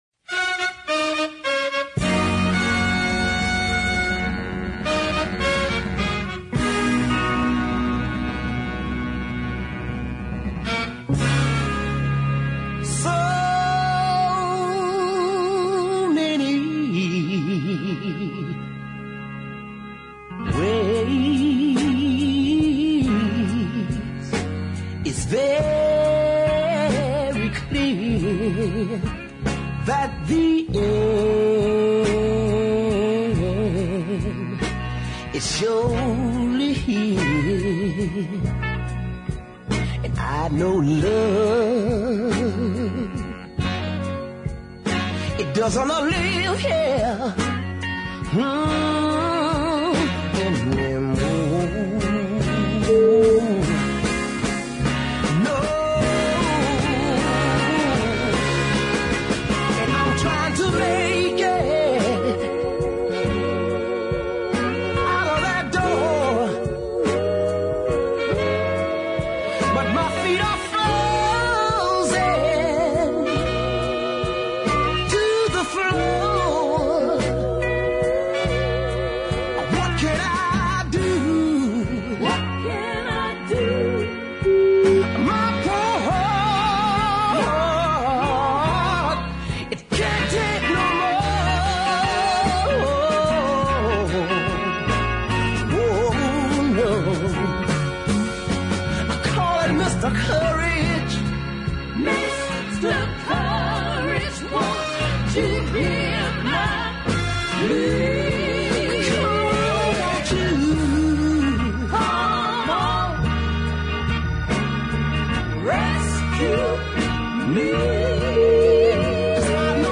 cut in New York City
This really is one hell of a deep record.